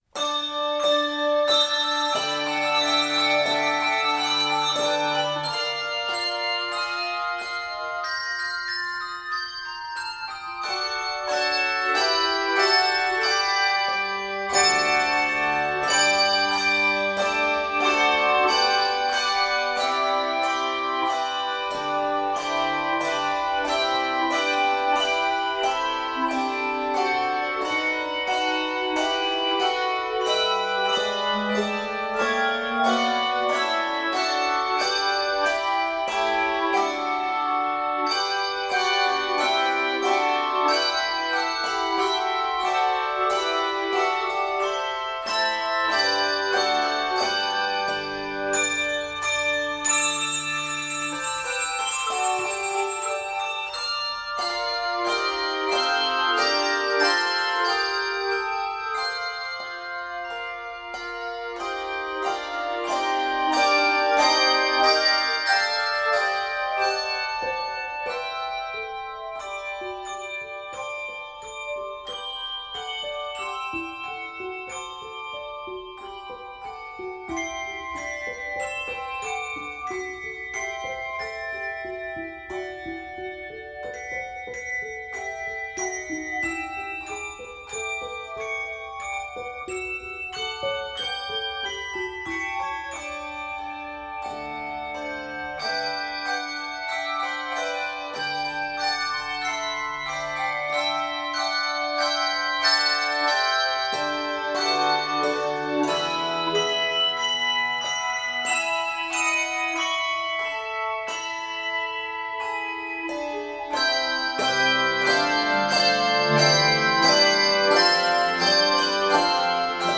This is a straightforward, three-stanza setting
Scored for 3–5 octave handbells.